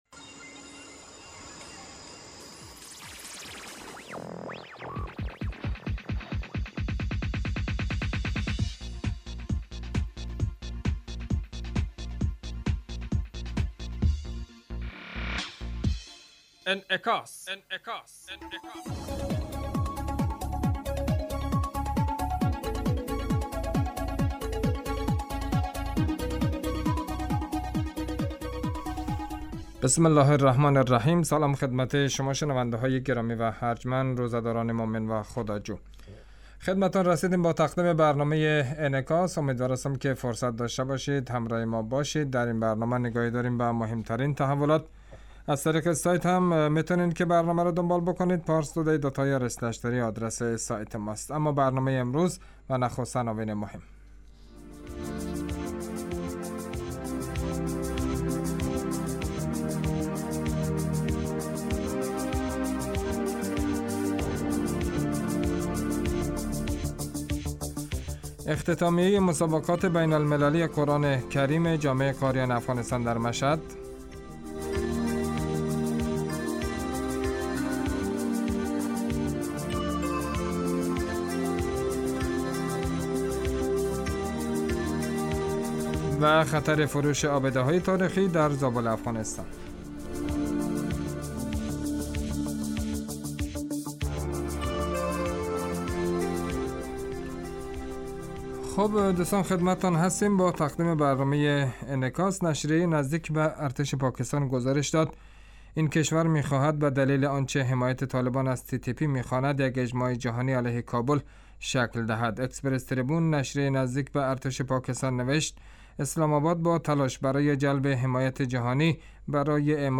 برنامه انعکاس به مدت 30 دقیقه هر روز در ساعت 06:50 بعد از ظهر (به وقت افغانستان) بصورت زنده پخش می شود.